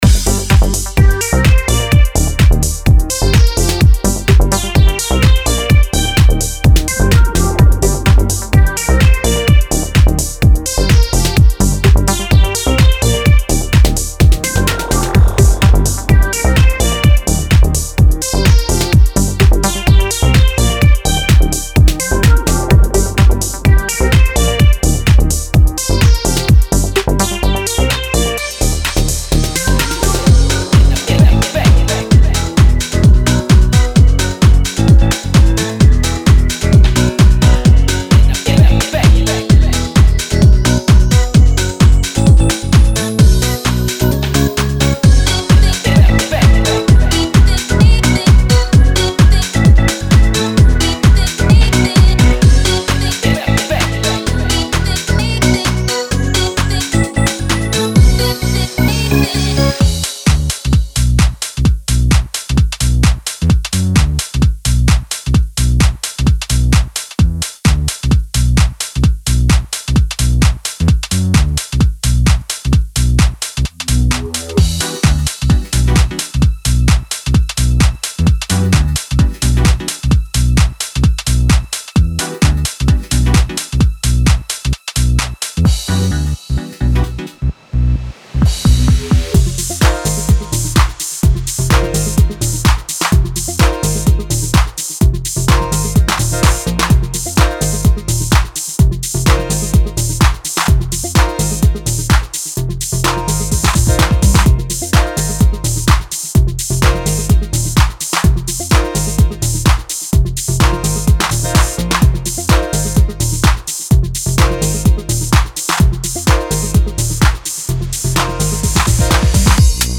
Genre:Classic House
35 Bass Loops
22 Synth Loops
8 Organ Loops
4 Vocal Chop Loops